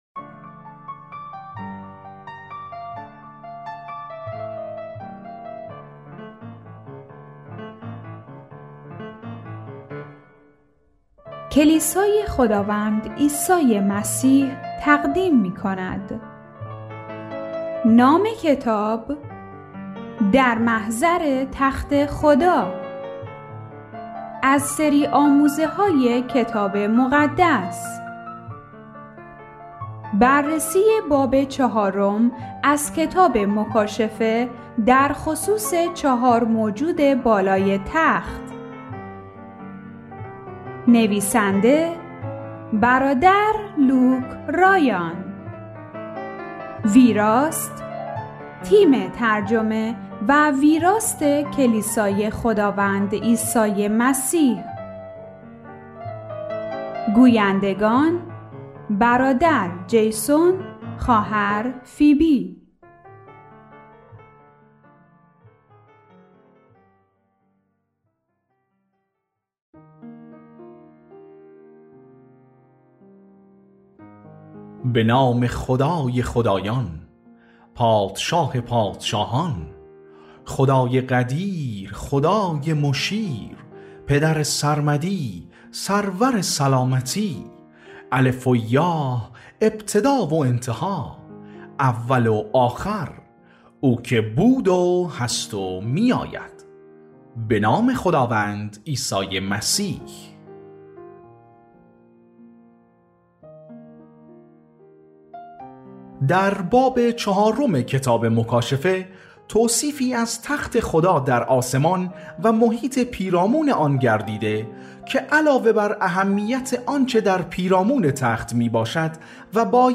پخش آنلاین و دانلود کتاب صوتی در محضر تخت خدا